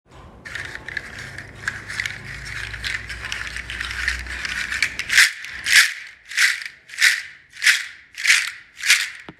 • large seed shaker originally from Togo
• loud hollow clacking sound
30 seed loop handle audio sample
Toga-loop-30-seed.m4a